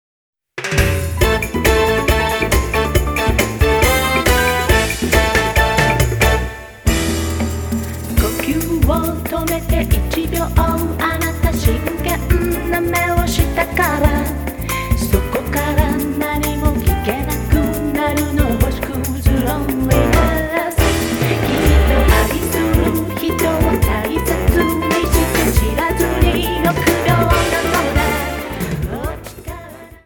女性ヴォーカリスト
16ビートのリズムで洒落た雰囲気のナンバーに仕上げている。